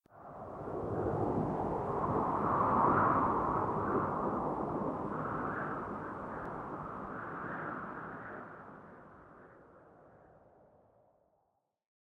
Wind sounds no longer end so abruptly.
wind_high.ogg